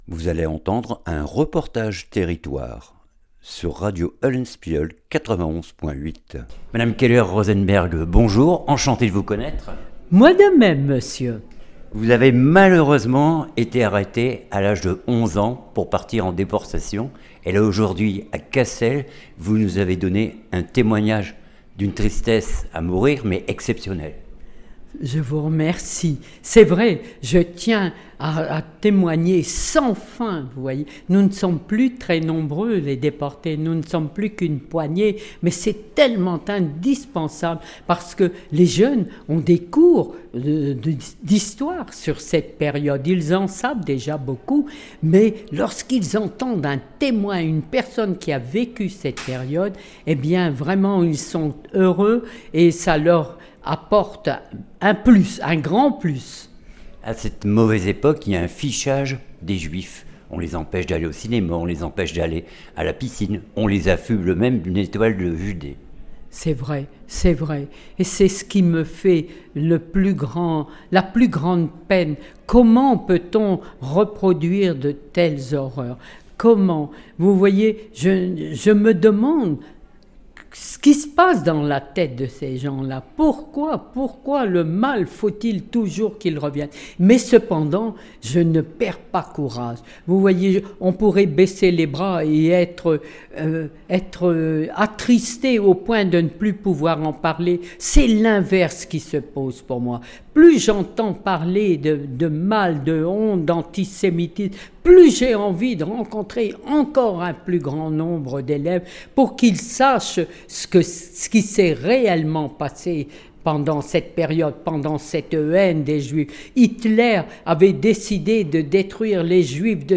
REPORTAGE TERRITOIRE